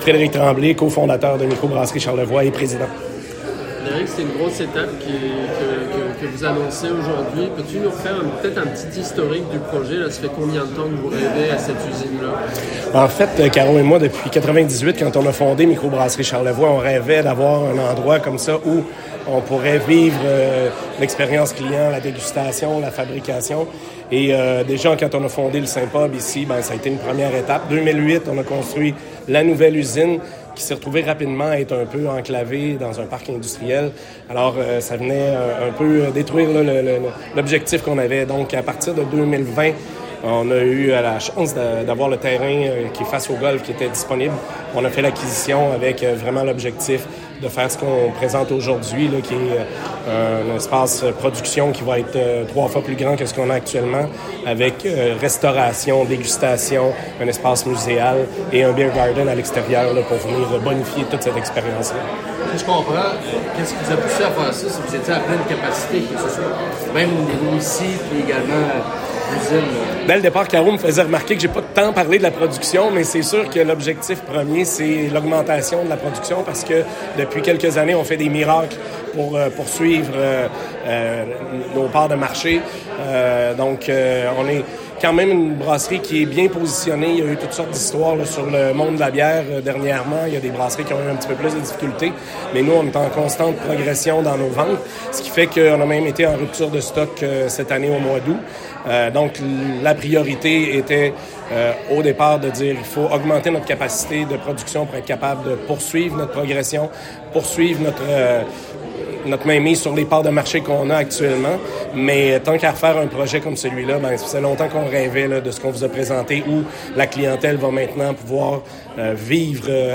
Point de presse impromptu